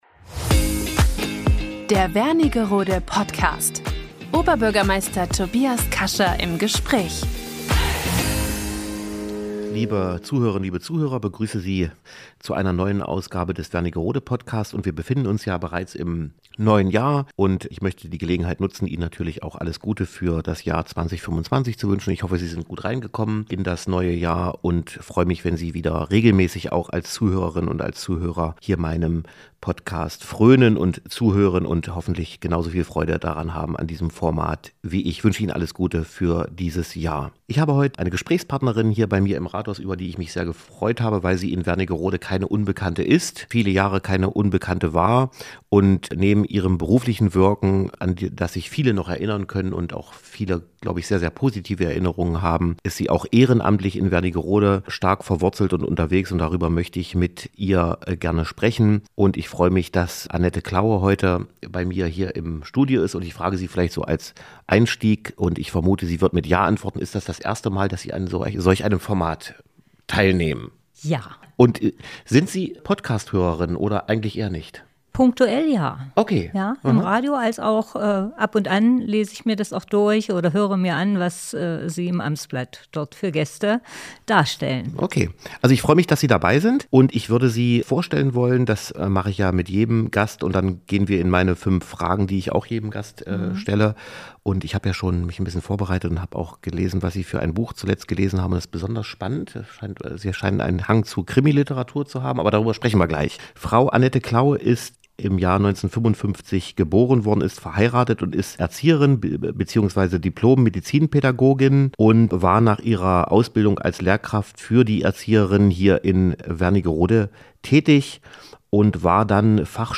Wernigerode Podcast #40 - Oberbürgermeister Tobias Kascha im Gespräch